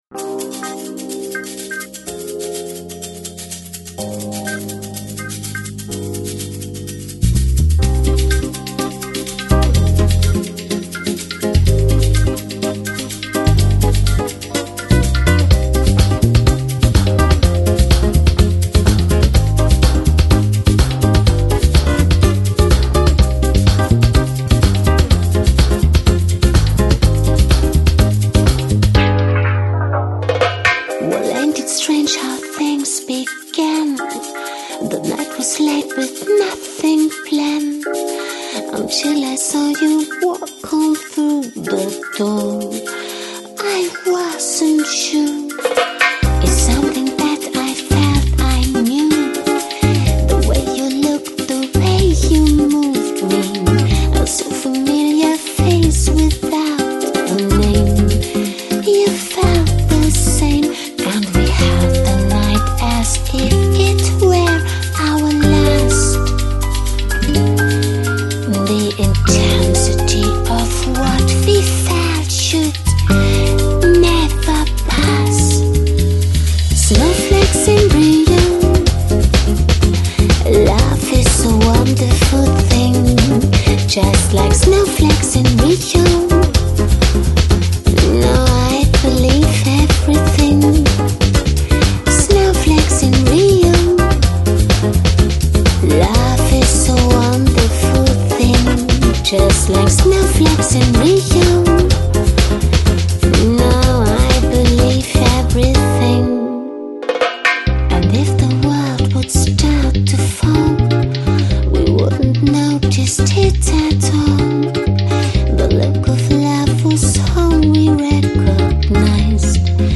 Жанр: Electronic, Lounge, Chill Out, Downtempo